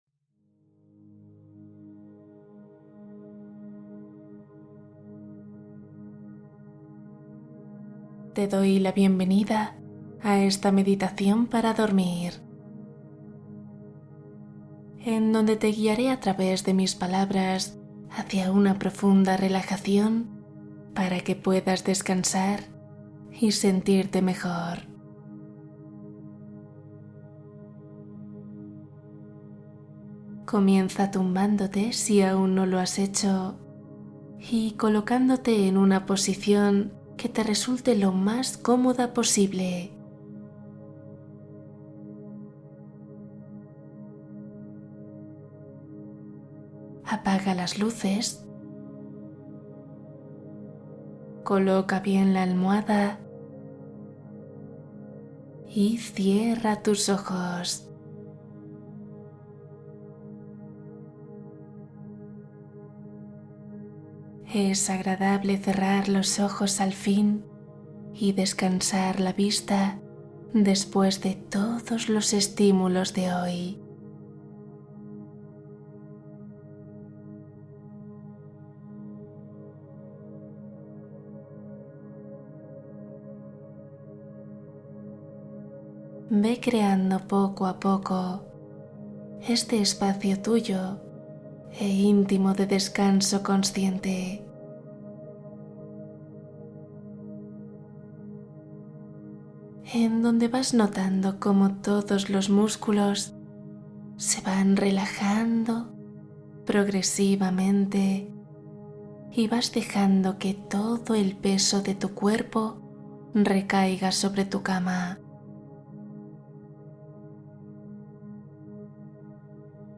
Propósitos del año ✨ Meditación y cuento para un sueño profundo